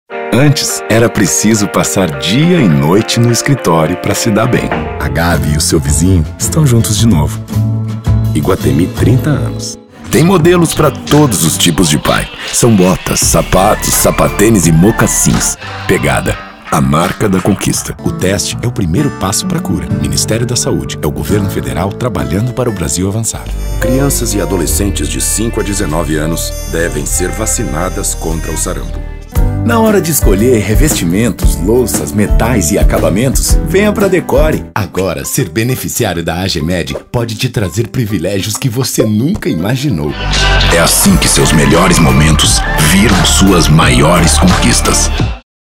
Masculino
Voz Padrão - Grave 00:45
Voz grave coloquial.